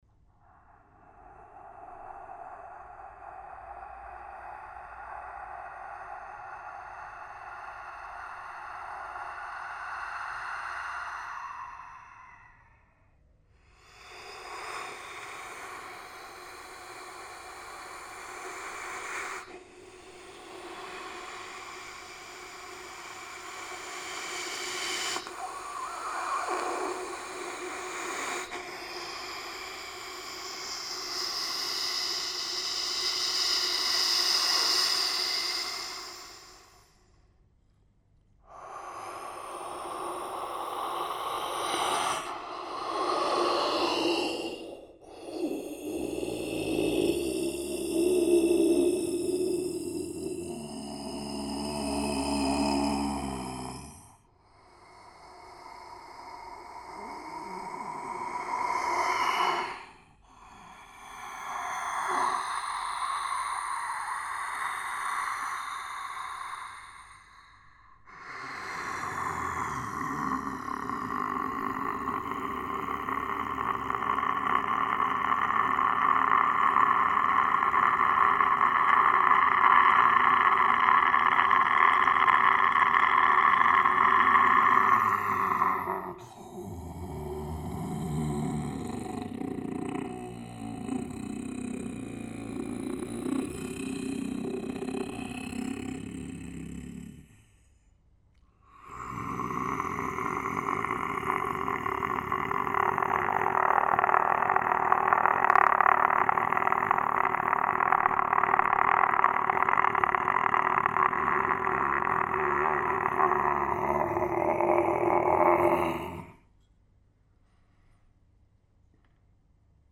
voice, megaphones, multi-track loop station